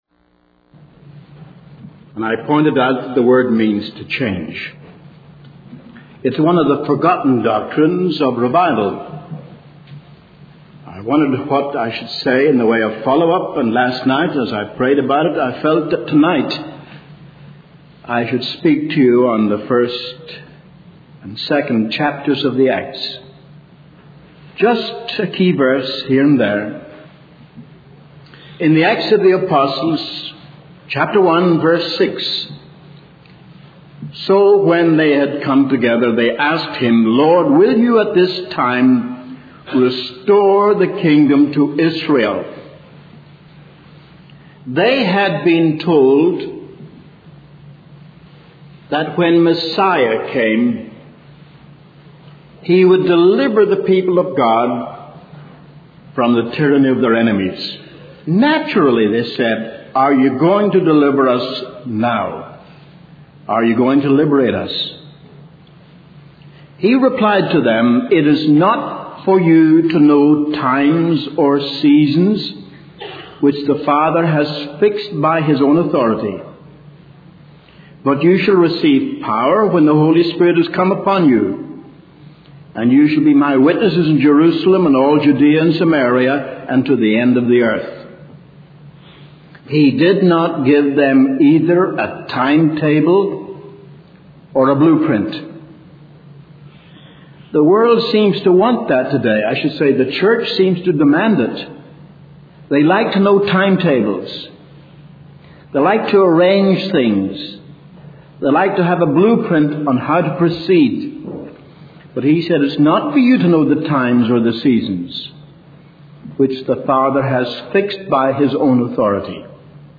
In this sermon, the preacher emphasizes the need for the overwhelming and convicting power of the Holy Spirit in today's wicked generation. He compares the current state of society to the revival of the Eisenhower years in the 1950s, highlighting the gross immorality, profanity, pornography, and crime prevalent today.